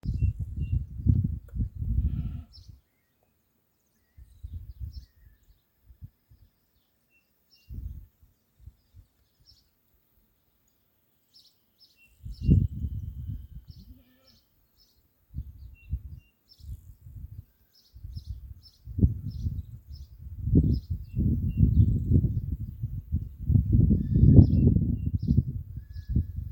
Striped Cuckoo (Tapera naevia)
Province / Department: Tucumán
Location or protected area: Trancas
Condition: Wild
Certainty: Recorded vocal